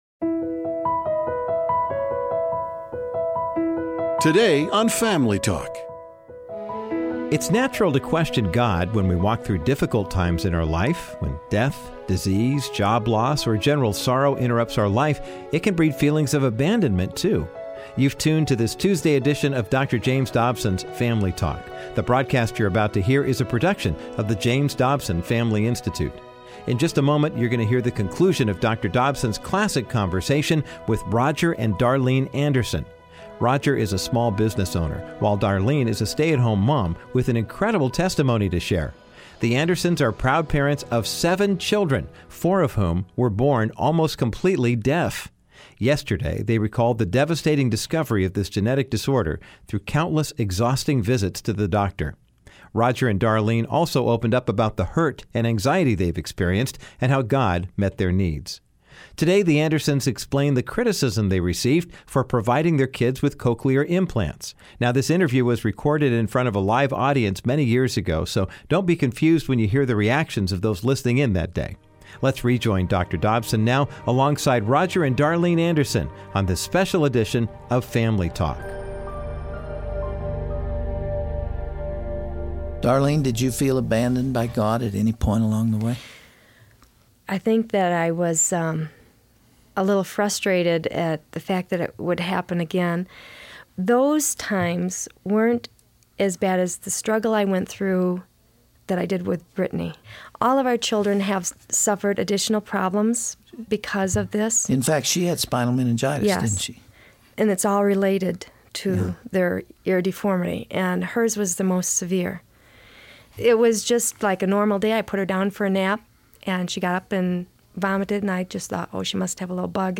How should Christians respond to unforeseen adversity or trials? On todays Family Talk broadcast